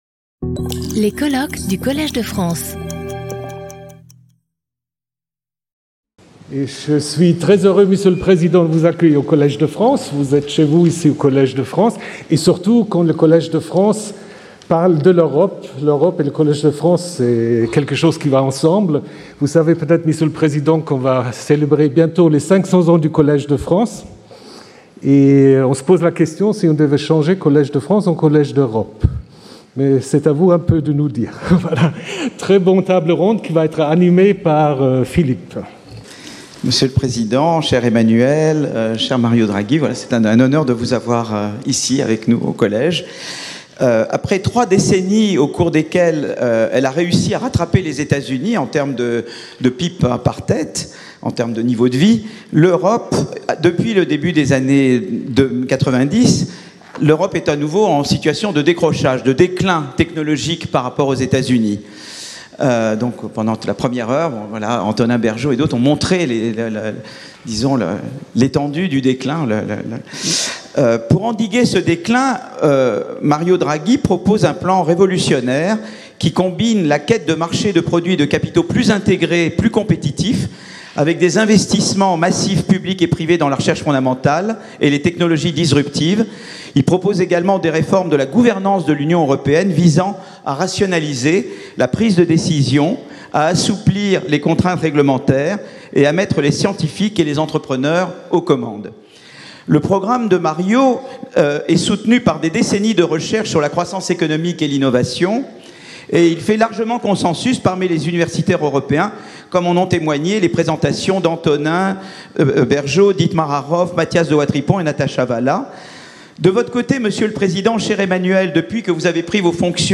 Sauter le player vidéo Youtube Écouter l'audio Télécharger l'audio Lecture audio Table ronde avec M. Emmanuel Macron , Président de la République, et le Pr Mario Draghi , ancien président du Conseil des ministres italien, ancien président de la Banque centrale européenne, animée par Philippe Aghion.